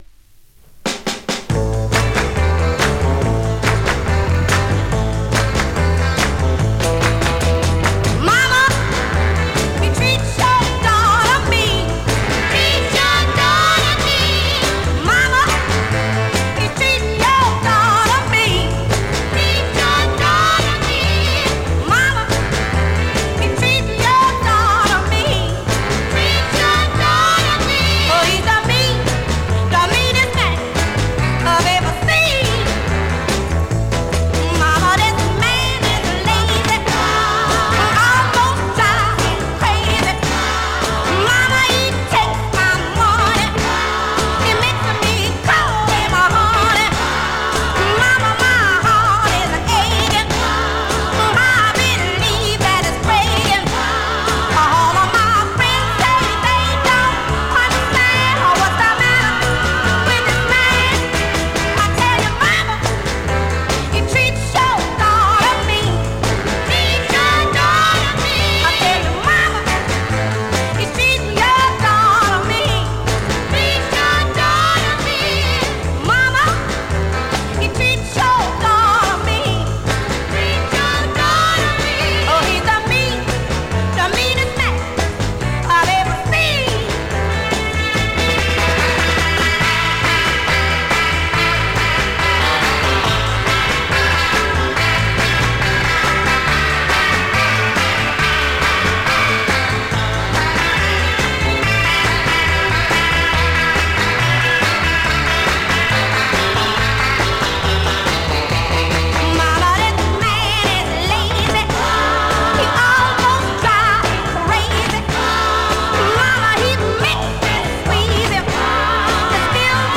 Vinyl has a few very light marks plays great .
Category: R&B, MOD, POPCORN